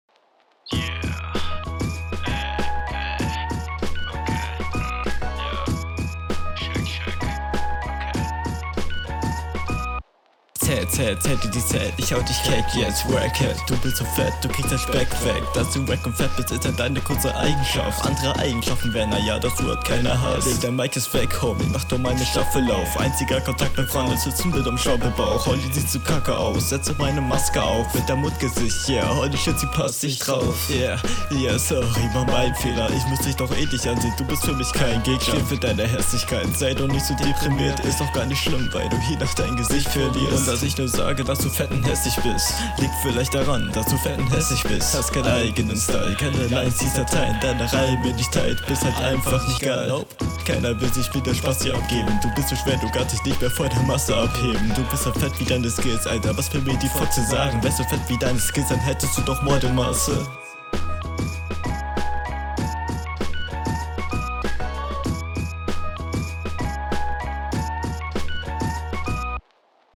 Flow: ist solider als in runde 1 und gefällt mir mehr Text: Die ganzen fetten …
Flow: Du hast zwar ganz nett gedachte Patterns, jedoch rappst du relativ undeutlich.